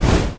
SFX item_card_fire_shooting.wav